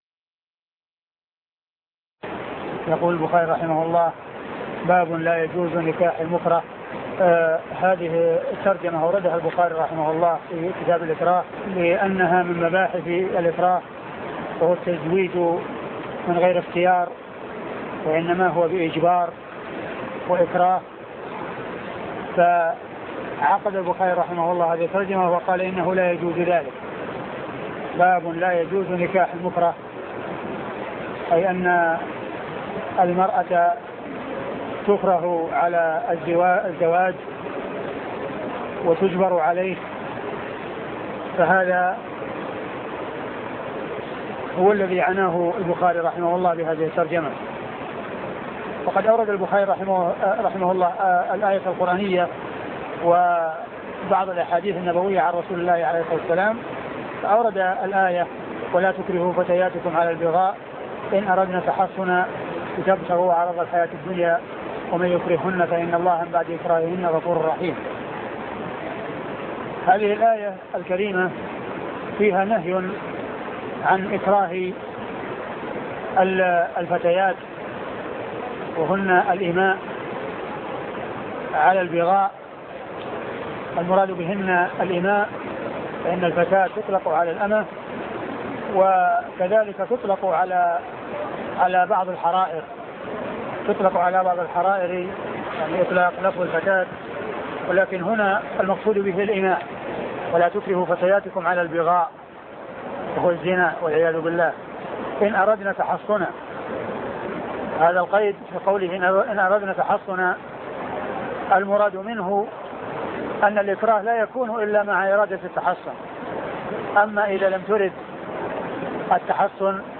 صحيح البخاري شرح الشيخ عبد المحسن بن حمد العباد الدرس 706